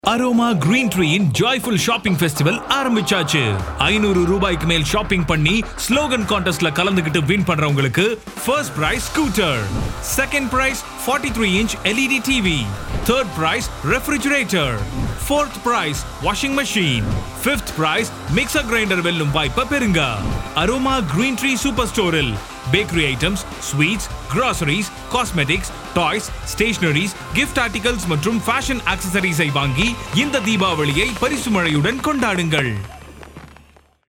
Aroma – Radio Commercial